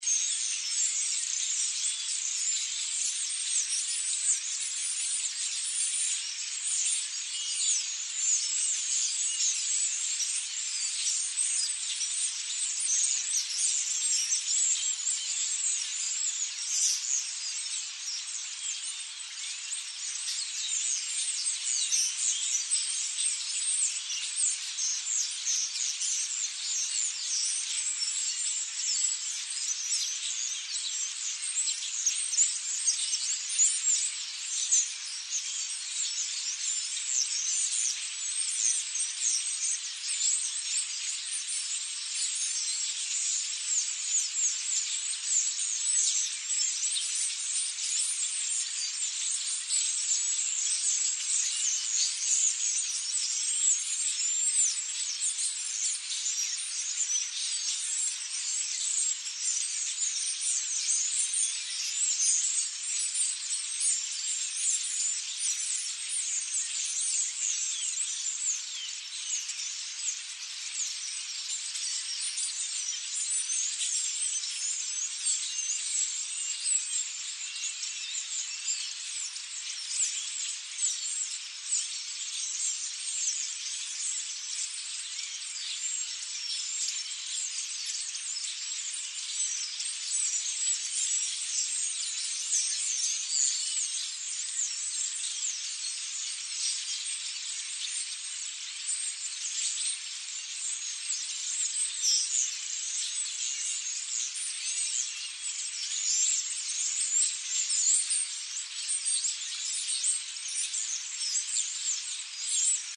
На этой странице собраны разнообразные звуки скворцов: от мелодичного пения до характерного свиста.
Звук стаи скворцов, поющих и свистящих